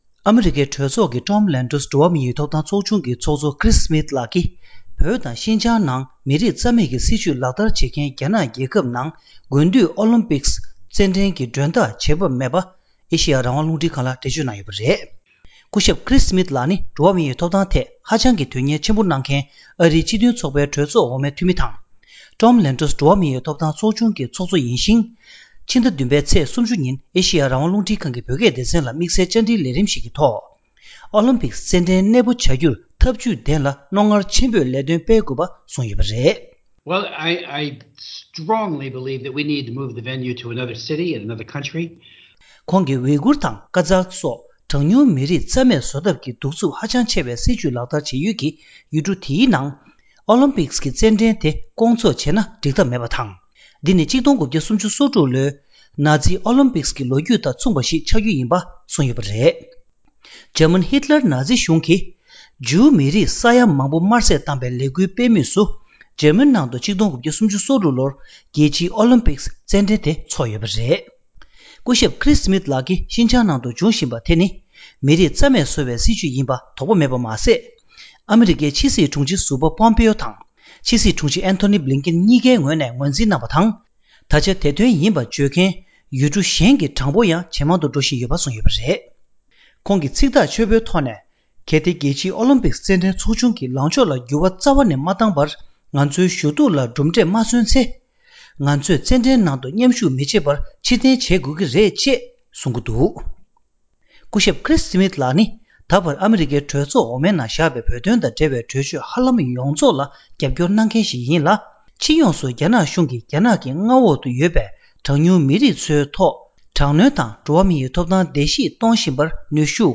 ཕྱི་ཟླ་ ༧ ཚེས་ ༣༠ ཉིན་ཨེ་ཤེ་ཡ་རང་དབང་རླུང་འཕྲིན་ཁང་གི་བོད་སྐད་སྡེ་ཚན་ལ་དམིགས་བསལ་བཅར་འདྲིའི་ལས་རིམ་ཞིག་གི་ཐོག་ཨོ་ལིམ་པིཀསི་རྩེད་འགྲན་གནས་སྤོ་བྱ་རྒྱུར་ཐབས་བྱུས་ལྡན་ལ་རྣོ་ངར་ཆེན་པོས་ལས་དོན་སྤེལ་དགོས་པ་གསུངས་ཡོད་པ་རེད།